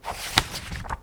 Page_Flip.wav